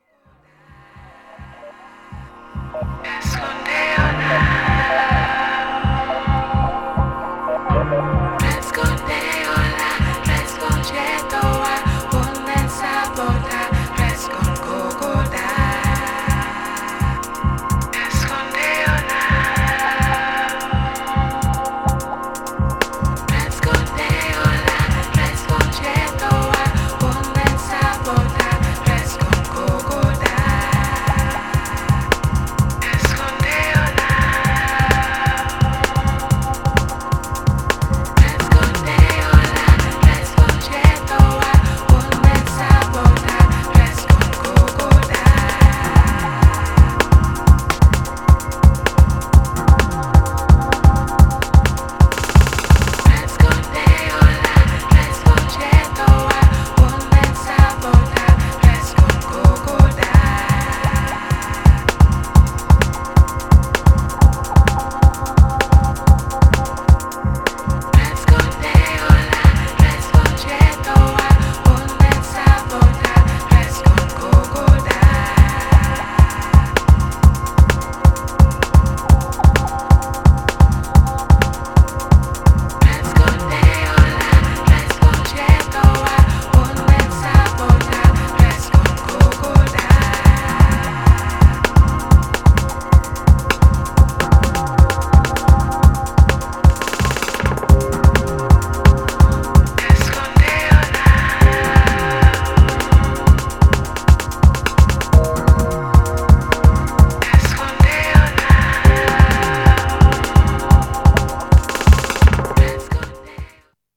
Styl: Drum'n'bass, House, Breaks/Breakbeat